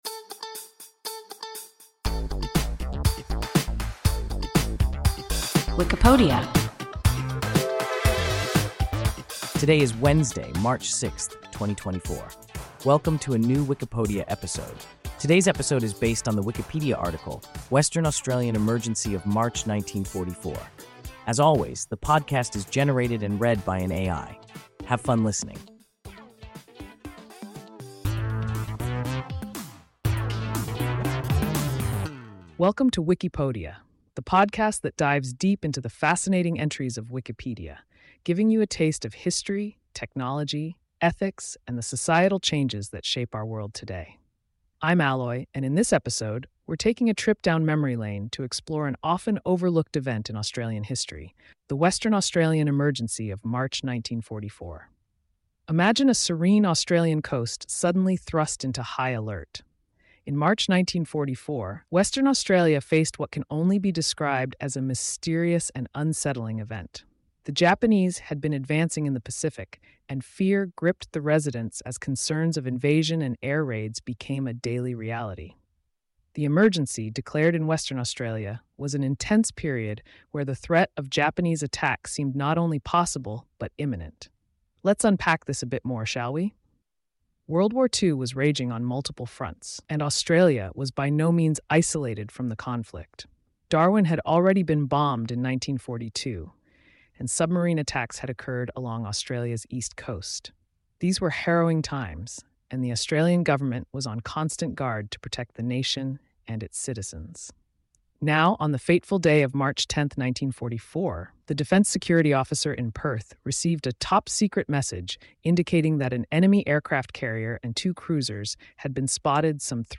Western Australian emergency of March 1944 – WIKIPODIA – ein KI Podcast